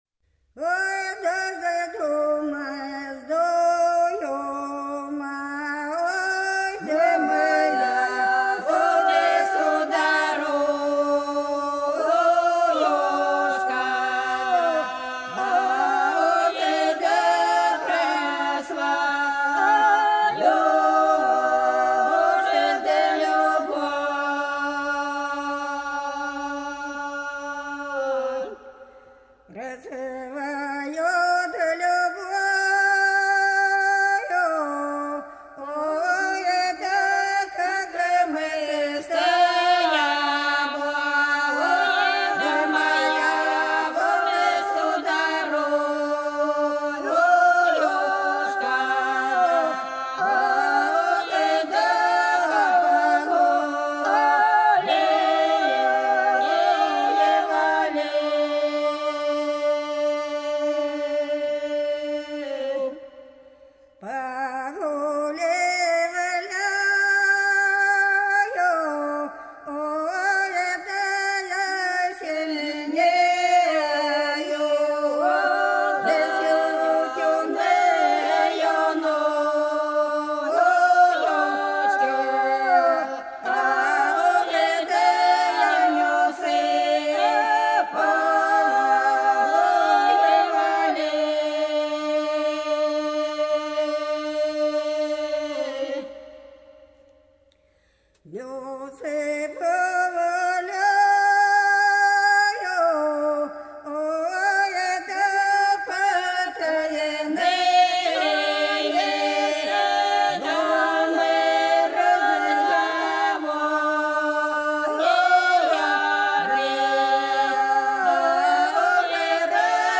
Долина была широкая (Поют народные исполнители села Нижняя Покровка Белгородской области) Вздумай, вздумай, да моя сударушка - протяжная